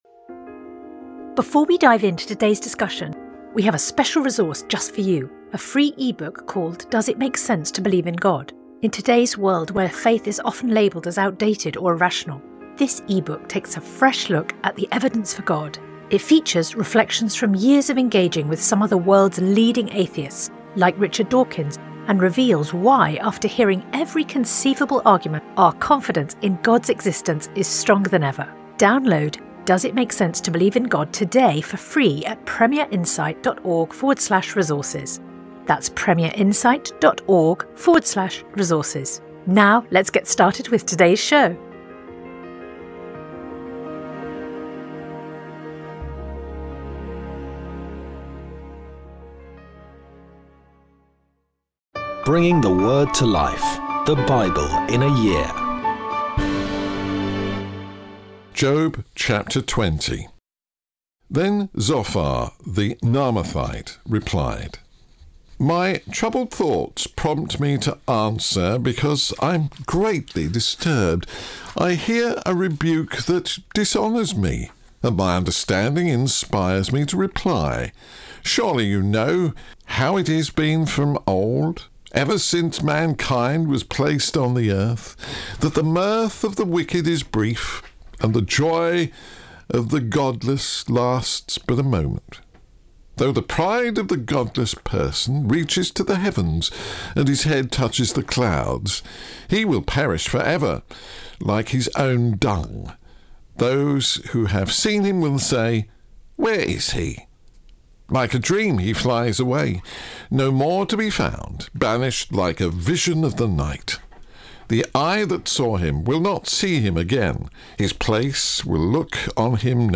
Today’s reading comes from Job 20-21; Colossians 1 Sponsored ad Sponsored ad